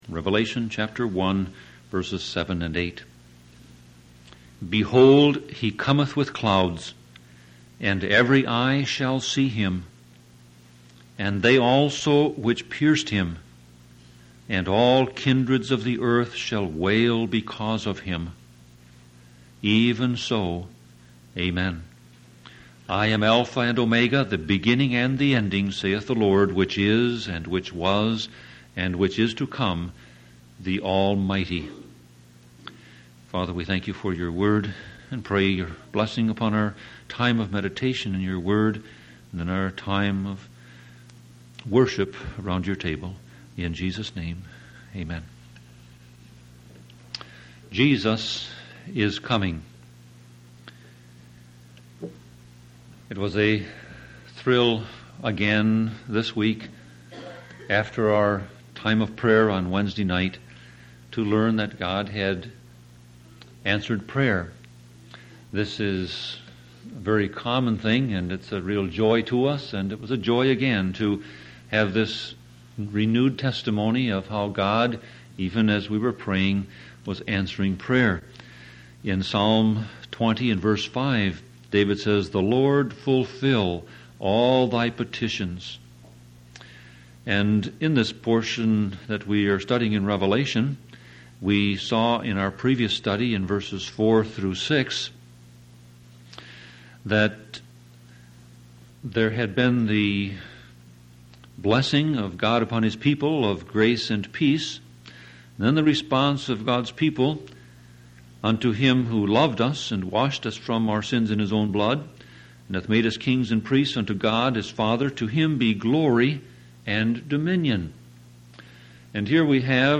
Sermon Audio Passage